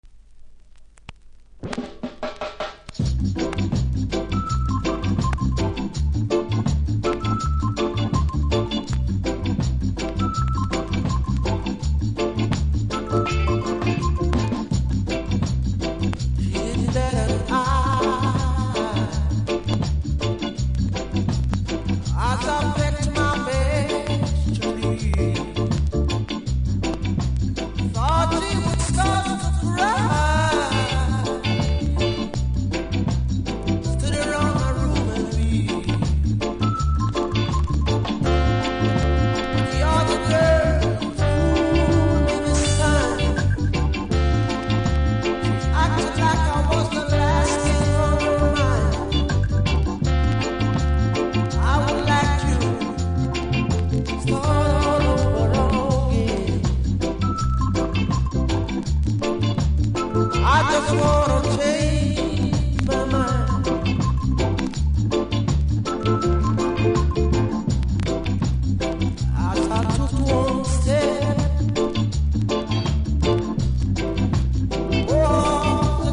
序盤キズによりノイズあり。